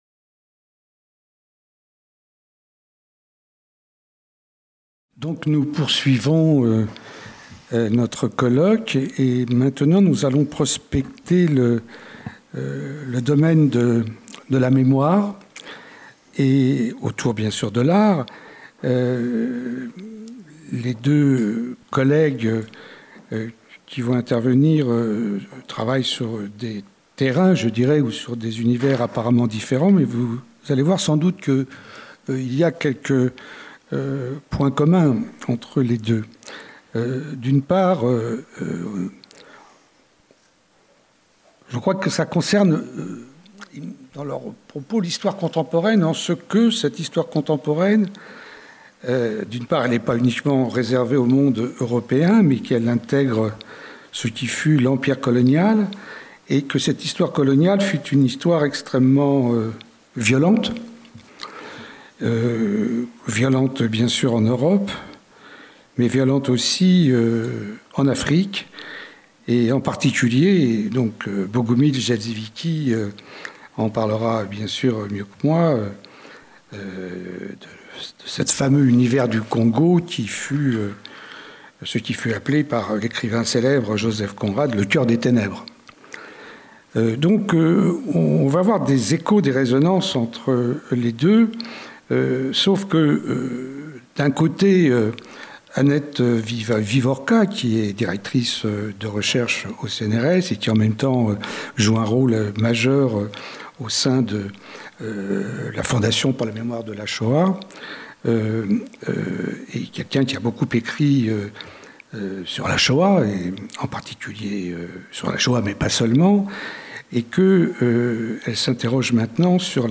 Jeudi 19 mai - Philharmonie, salle de conférence 15H50 Art et mémoire : écrire l’histoire : une création oulipienne par Annette WIEVIORKA